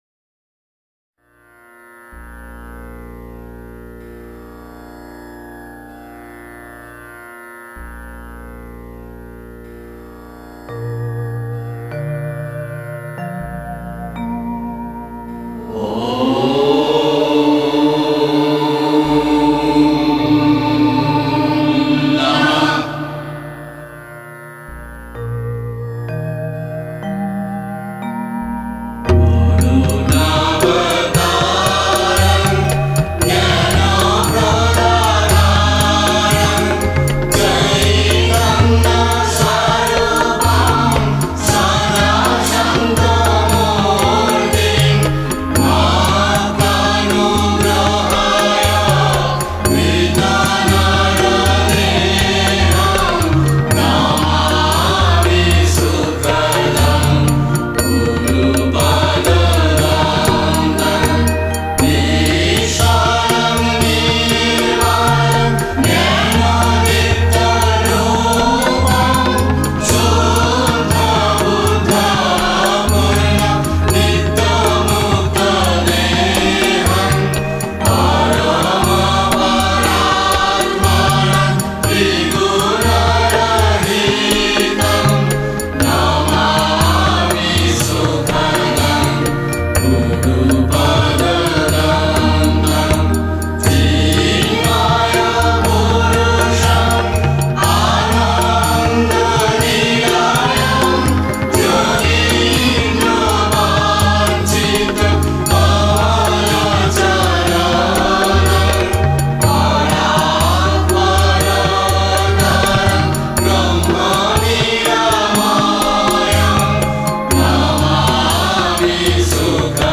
Prarthana_Sangeet.mp3